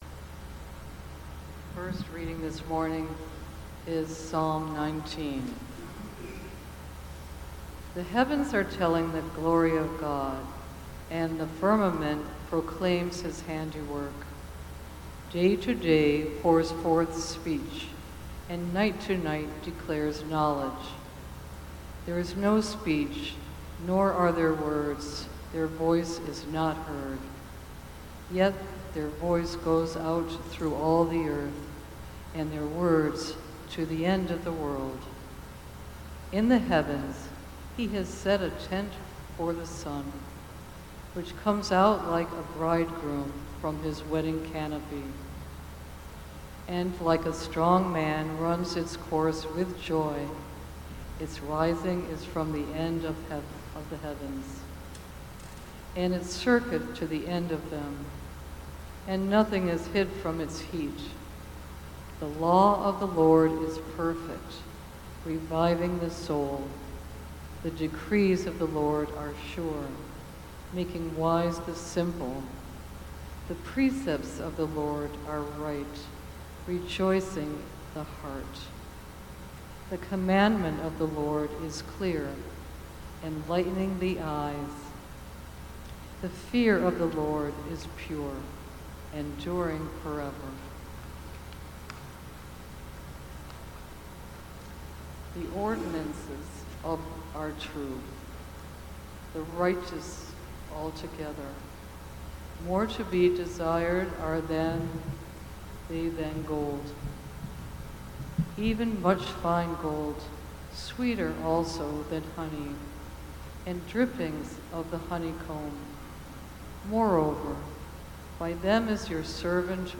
2024 Takin it to the streets Preacher